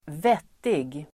Uttal: [²v'et:ig]